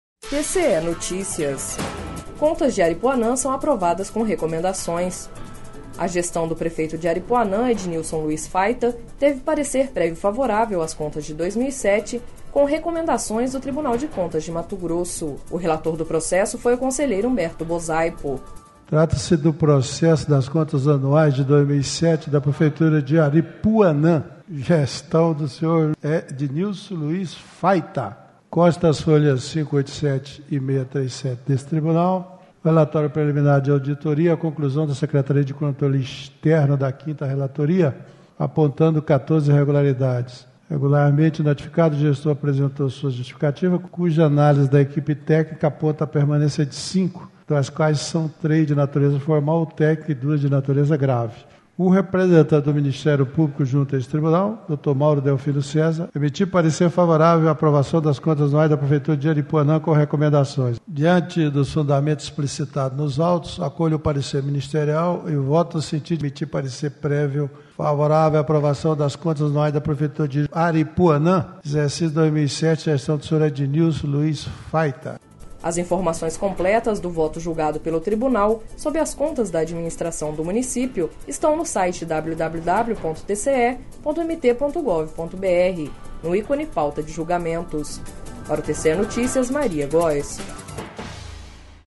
Sonora: Humberto Bosaipo - conselheiro presidente do TCE-MT